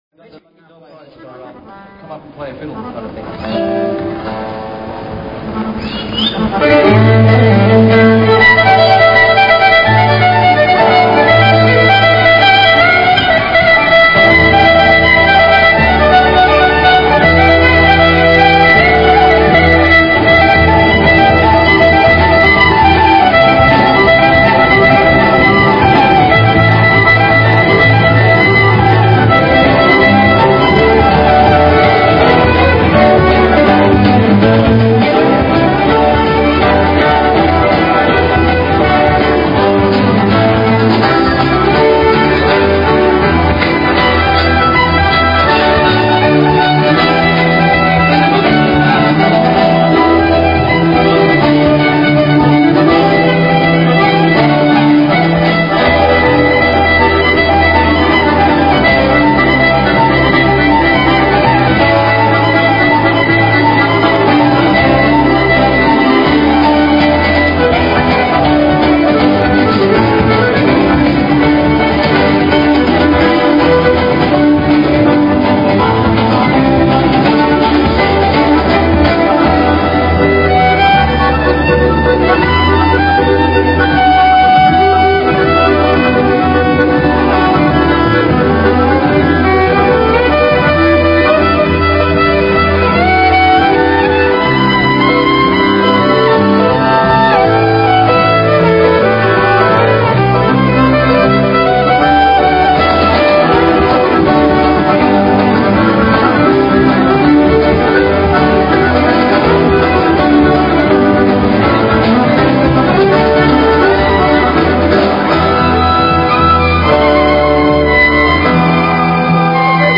Ci spostiamo in una piccola piazza dove sono disposte parecchie sedie e sul marciapiede di fronte vedo amplificatori e microfoni.
Infatti dopo un attimo arrivano un po' di musicisti che iniziano a suonare dalla musica country a quella irlandese a pezzi di classica, ci sono sempre almeno tre o quattro violinisti accompagnati da un contrabbasso, batteria, chitarra o fisarmonica o tastiera elettronica.
Praticamente tiro sera entusiasmato dagli assoli che a turno fanno i musicisti, impegnati all'estremo nell'esecuzione dei giri più virtuosi ed impegnativi, come se fosse una gara,con un risultato a dir poco eccezionale.